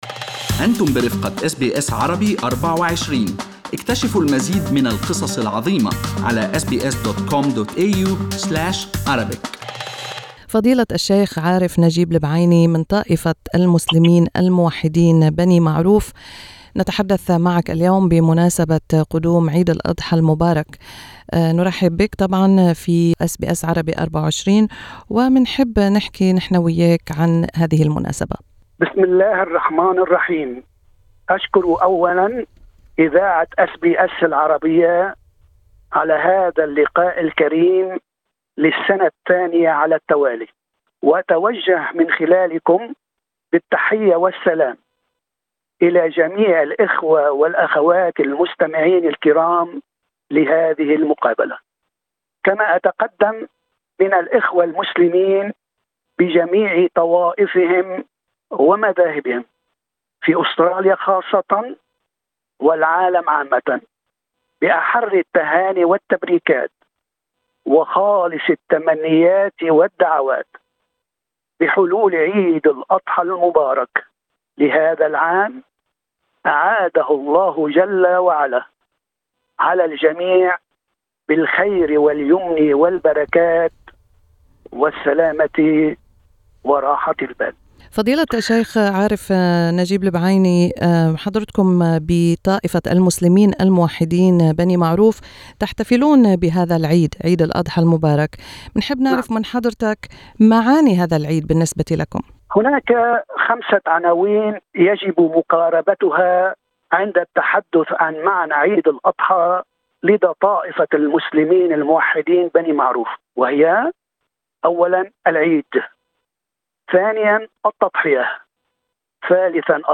شيخ من طائفة المسلمين الموحدين يشرح معاني وعناوين عيد الأضحى المبارك